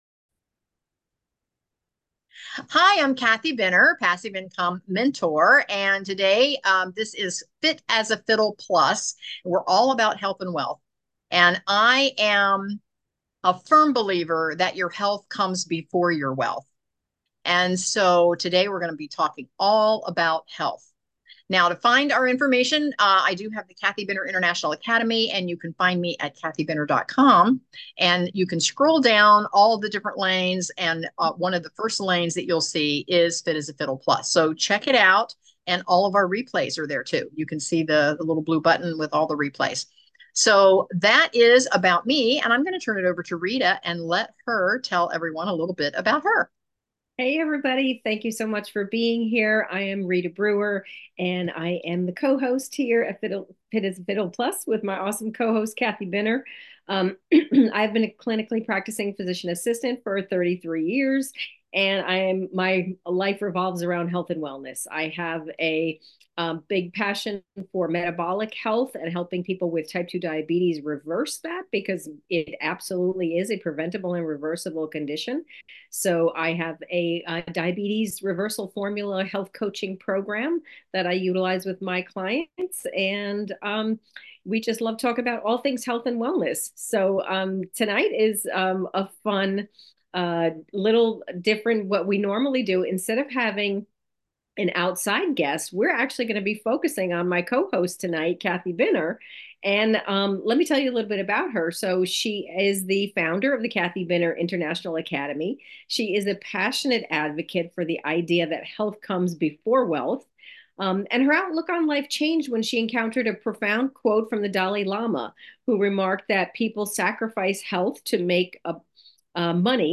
Health and Wellness | Fit as a Fiddle Plus